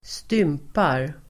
Uttal: [²st'ym:par]